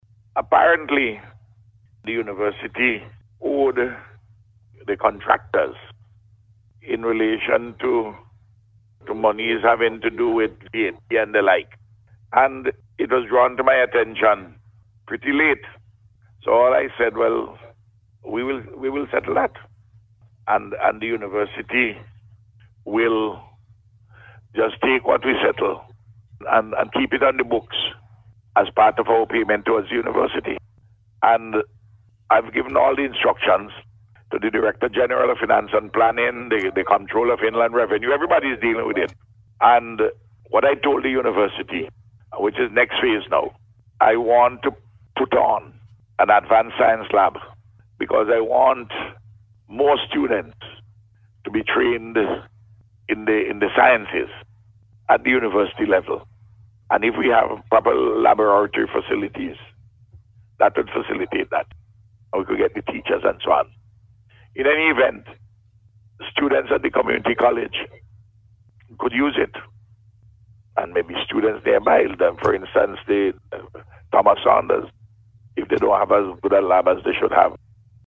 That is according to Prime Minister Dr. Ralph Gonsalves who made the announcement on Radio on Friday where he addressed concerns about the delay in the inauguration of the facility.